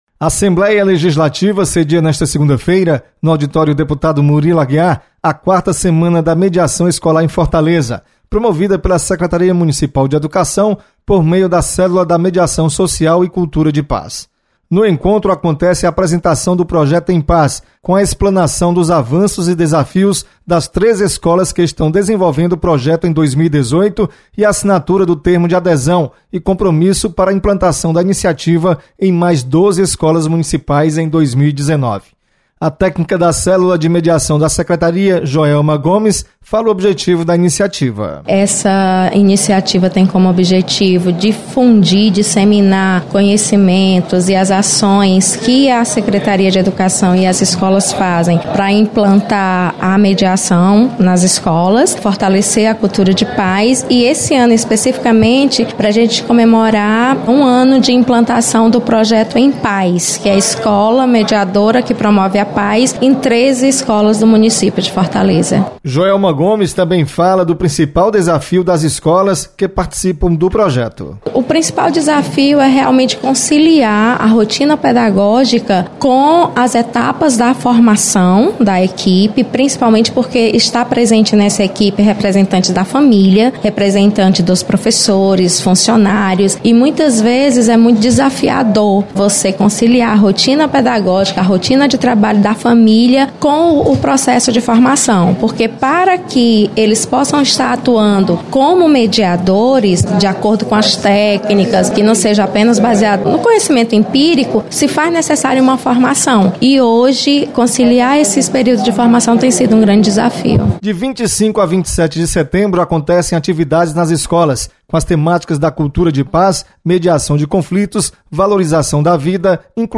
Assembleia Legislativa sedia Quarta Semana da Mediação. Repórter